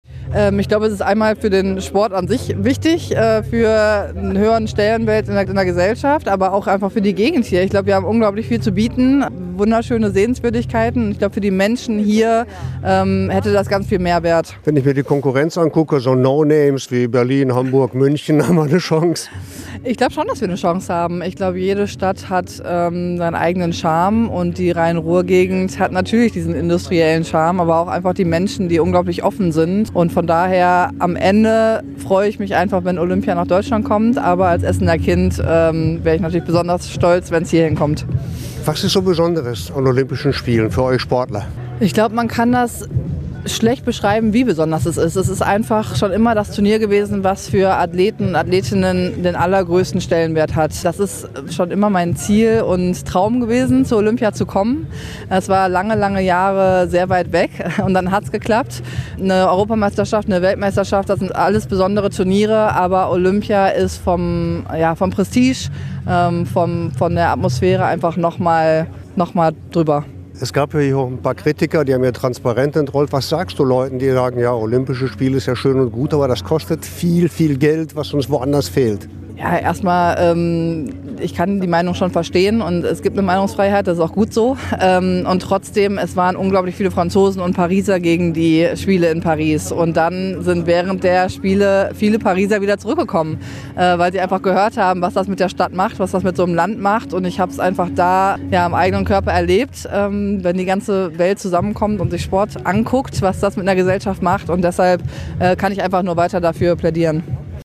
In Radio Essen Interview erzählt die Athletin warum sich das Ruhrgebiet zusammen mit dem Rheinland um die Olympischen Spiele bewerben sollten.